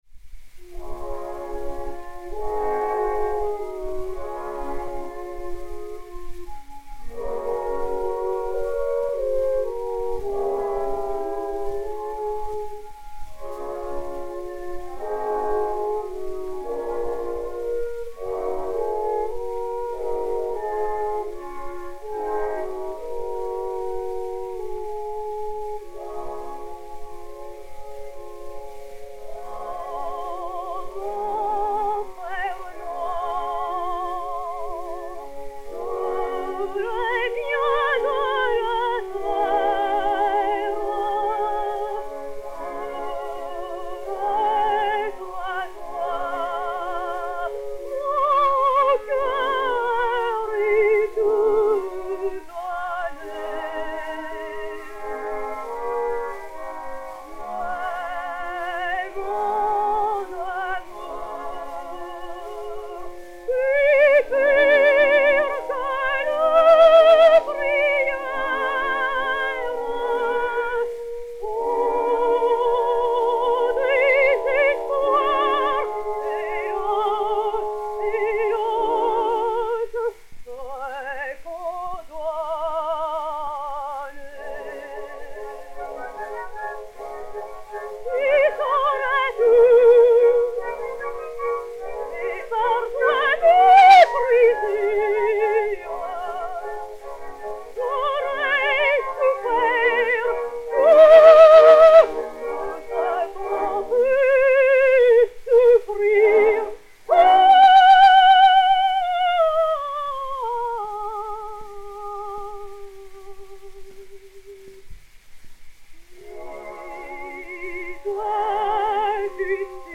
Yvonne Brothier, Suzanne Brohly et Orchestre
BE76-1, enr. à Paris le 24 octobre 1921